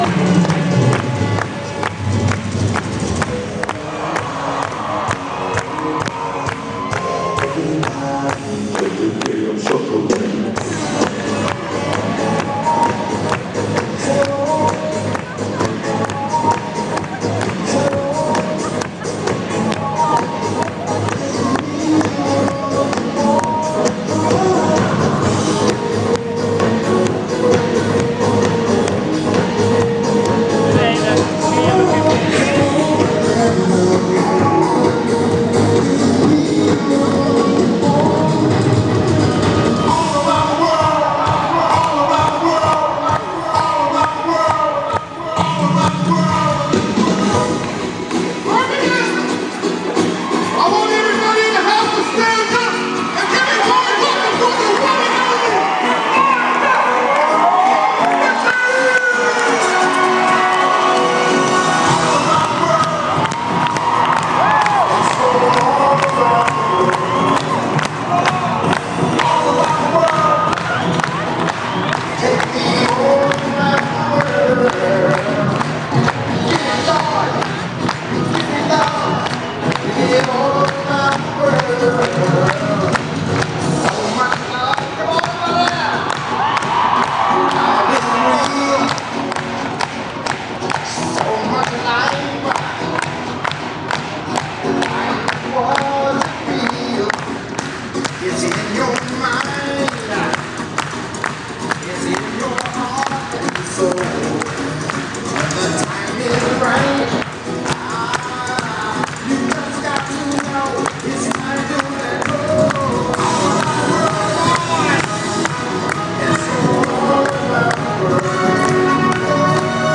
Opening from last nights concert.